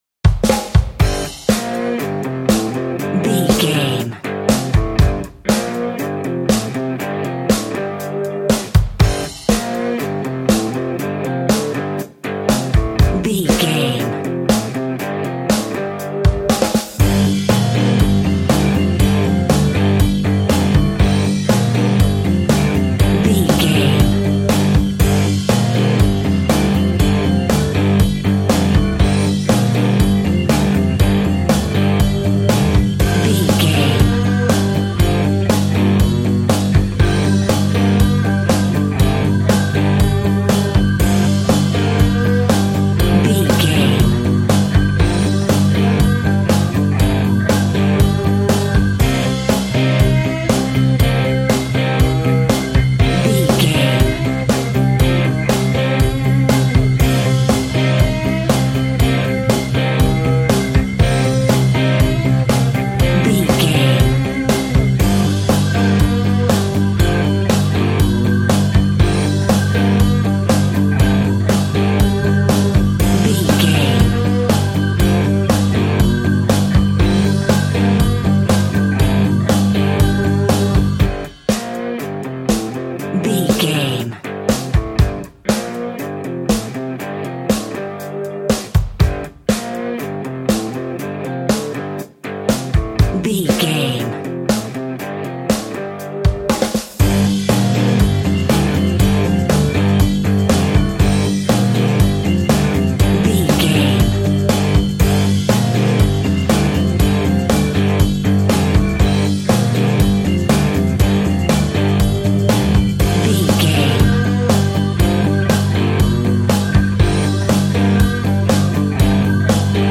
Aeolian/Minor
happy
electric guitar
bass guitar
drums
piano
organ